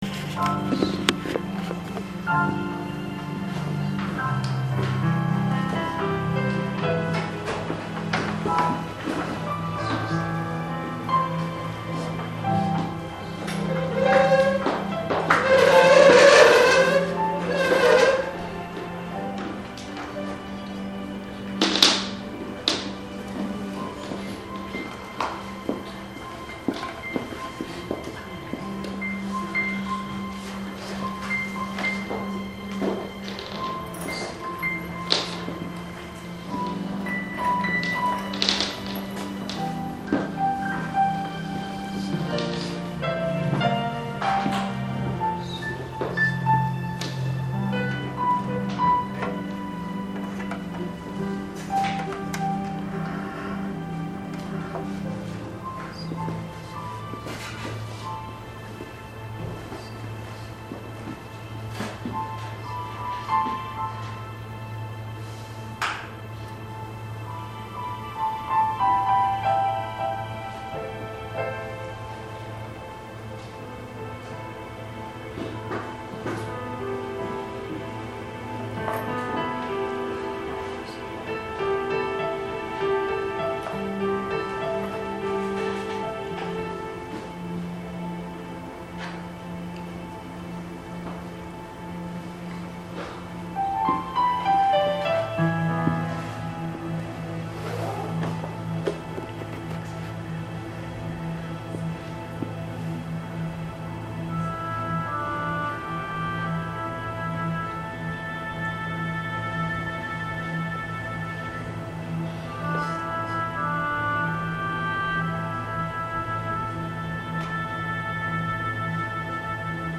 正在播放：--主日恩膏聚会录音（2016-06-12）